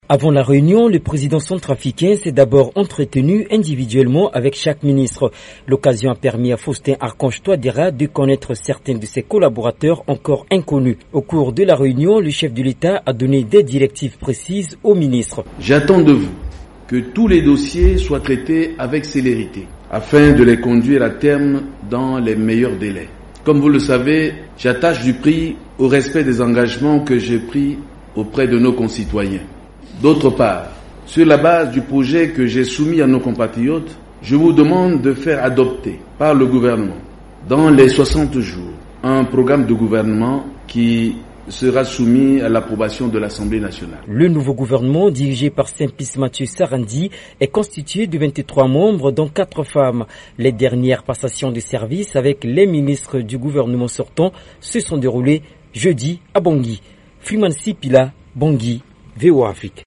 Le reportage
à Bangui